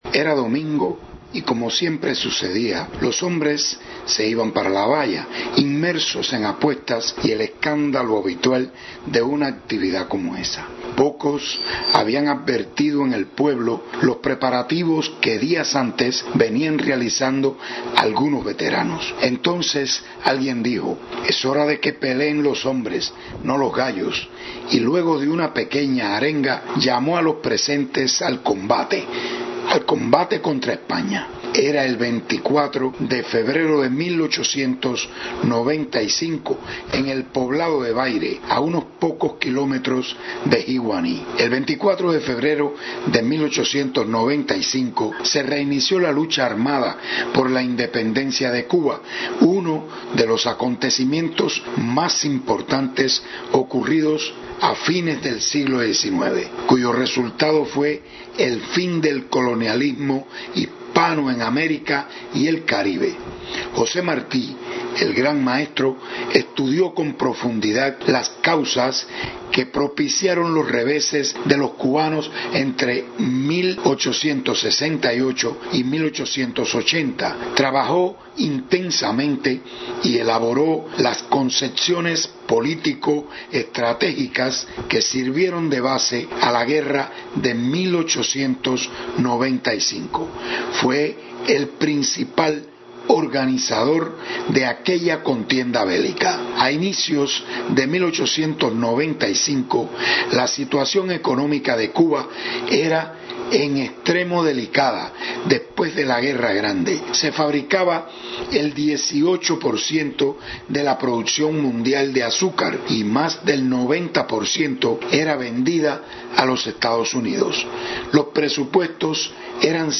Este 24 de febrero no será la excepción, con una fría mañana como es característica en este altiplano- a más de 500 metros sobre el nivel del mar- cientos de personas festejan la fecha y algunos descendientes de aquellos valientes, rememoran anécdotas que se mantienen vivas en la tradición oral después de 129 años.